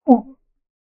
male_drown2.ogg